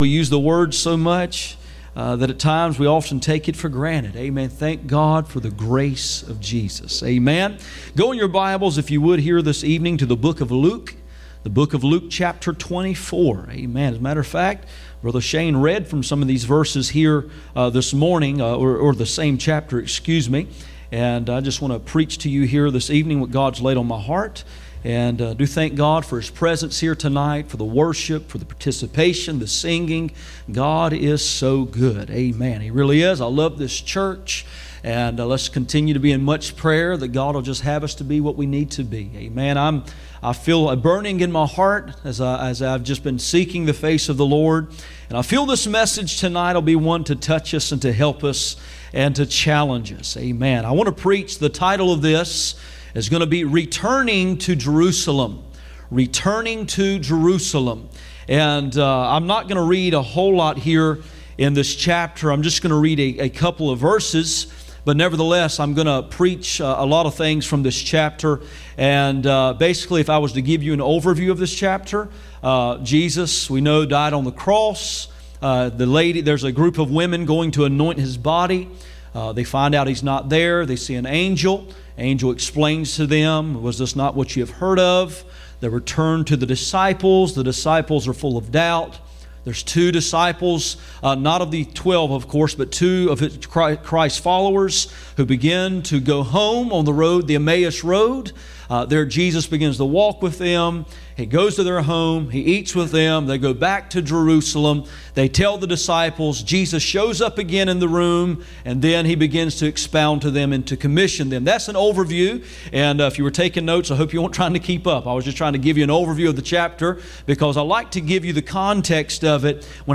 Passage: Luke 24:13-49 Service Type: Sunday Evening